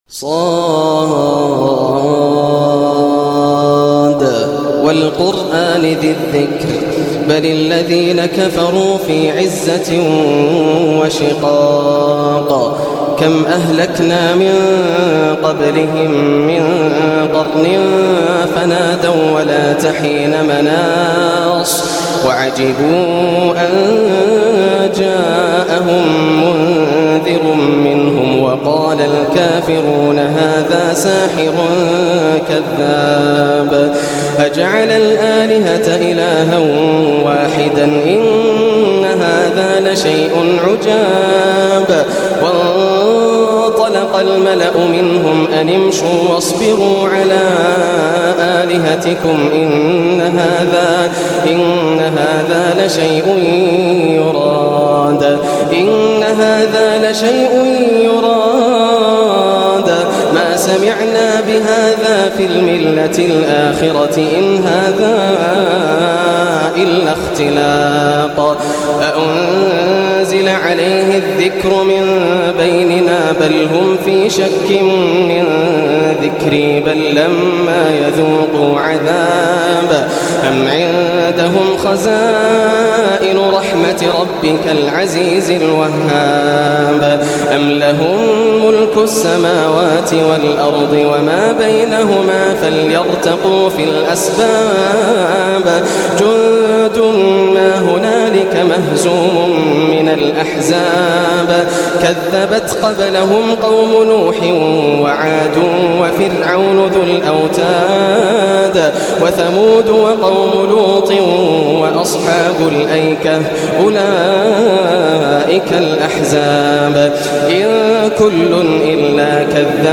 سورة ص > السور المكتملة > رمضان 1425 هـ > التراويح - تلاوات ياسر الدوسري